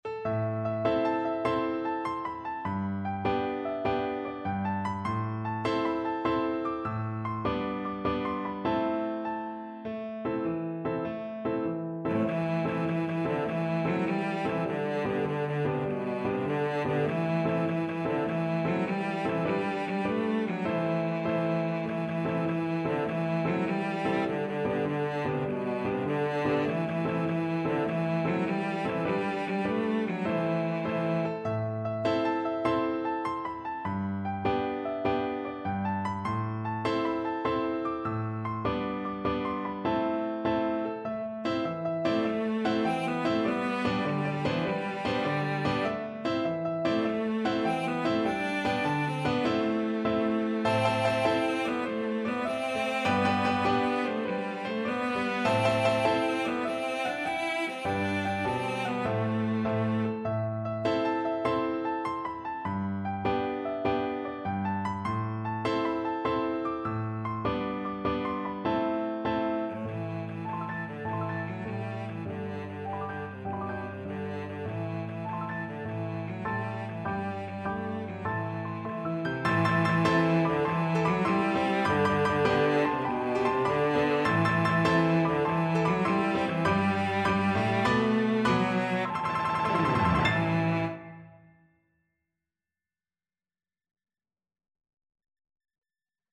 Cello version
Allegro .=c.100 (View more music marked Allegro)
6/8 (View more 6/8 Music)
Traditional (View more Traditional Cello Music)